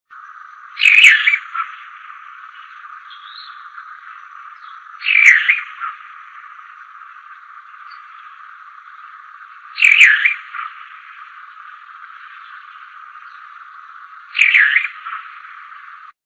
Boyero Negro (Cacicus solitarius)
Nombre en inglés: Solitary Cacique
Localidad o área protegida: Reserva Ecológica Costanera Sur (RECS)
Condición: Silvestre
Certeza: Observada, Vocalización Grabada
RECS-Boyero-Negro-1.mp3